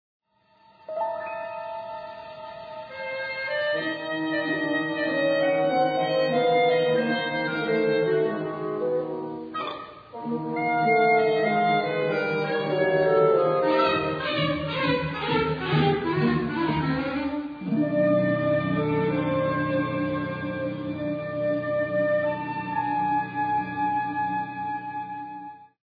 gently jazzy
" and its more lushly romantic variant